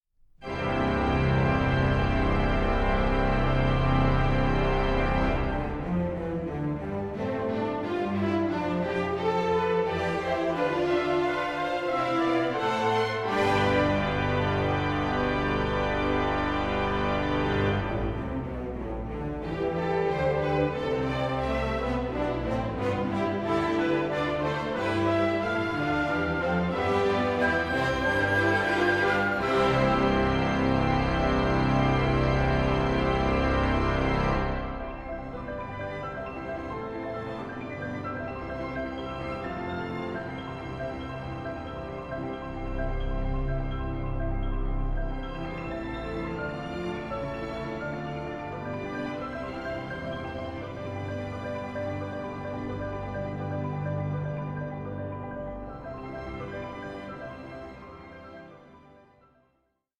MONUMENTAL AND COLOURFUL
The Geneva Victoria Hall organ